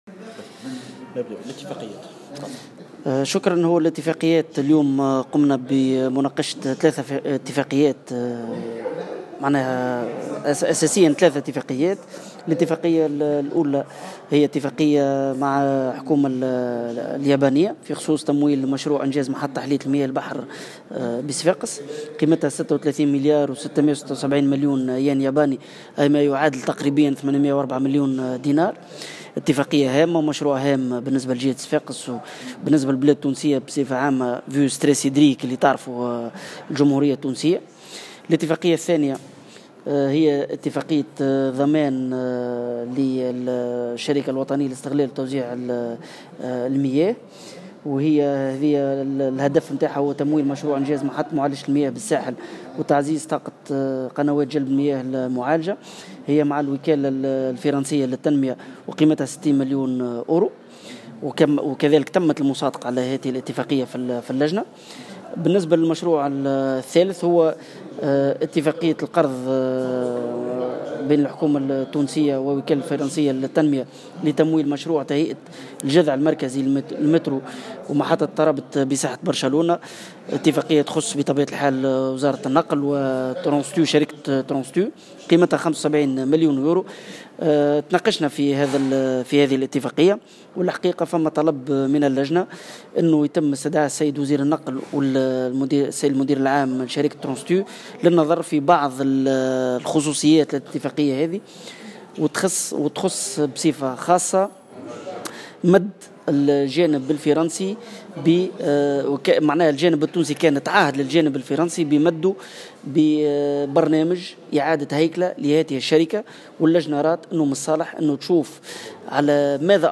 أكد مقرر لجنة المالية حسام بونني في تصريح لمراسل الجوهرة اليوم الأربعاء 3 جانفي 2018 أنه تم اليوم مناقشة 3 اتفاقيات والمصادقة على 2 منها فقط.